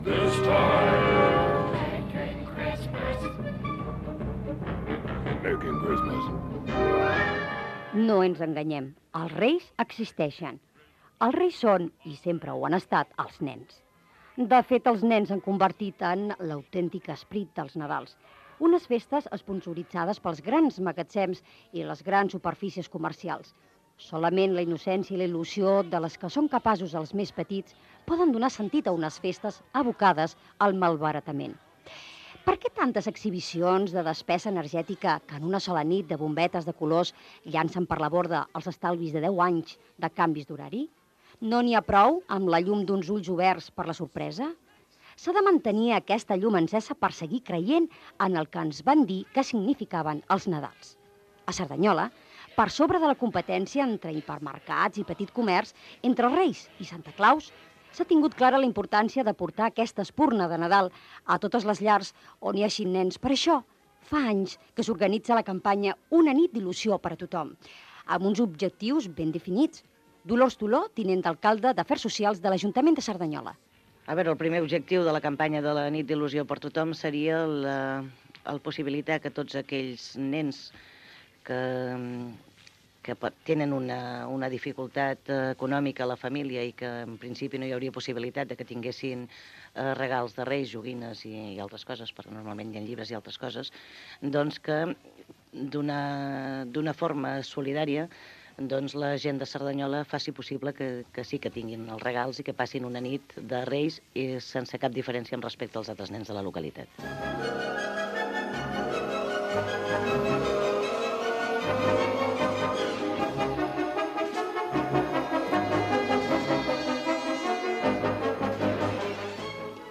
Crònica de la nit de Reis des de Cerdanyola Ràdio
FM